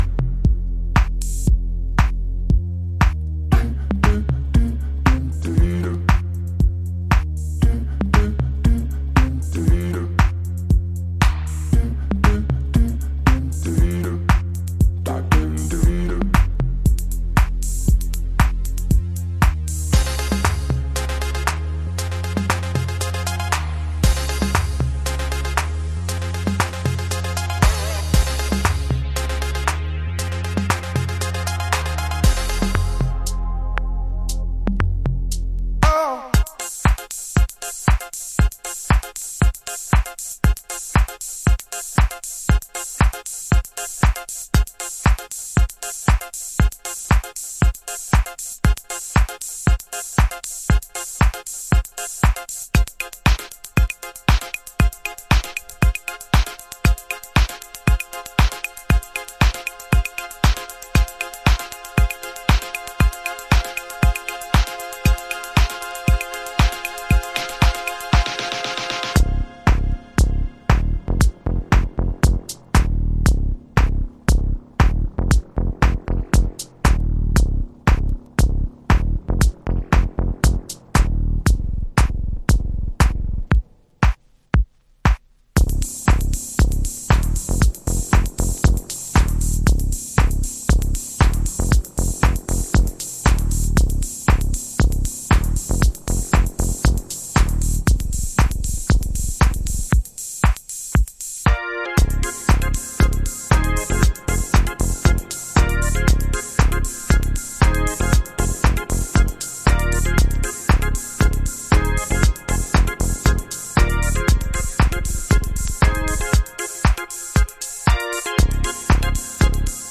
Alt Disco / Boogie